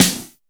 Tuned snare samples Free sound effects and audio clips
• Piercing Snare Drum Sound F# Key 370.wav
Royality free snare one shot tuned to the F# note. Loudest frequency: 4288Hz
piercing-snare-drum-sound-f-sharp-key-370-YkW.wav